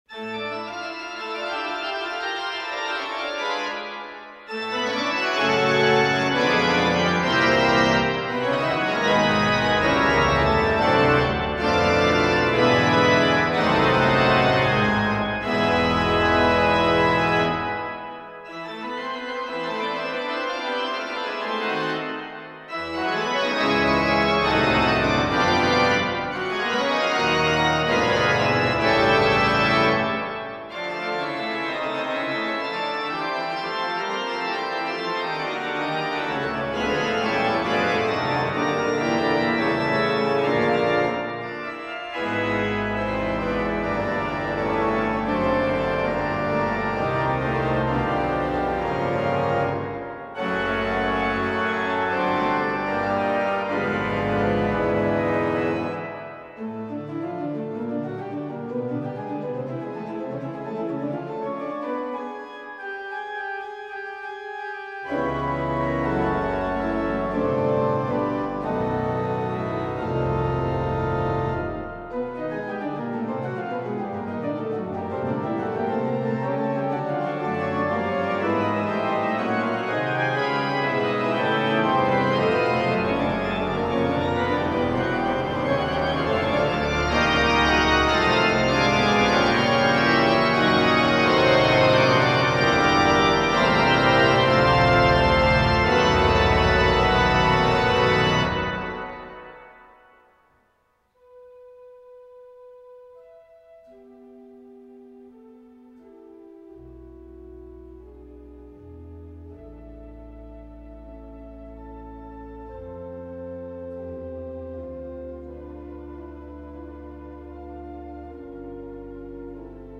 Die neue Orgel der St. Nikolai Kirche in Bad Liebenwerda
Porträt II einer symphonischen Orgel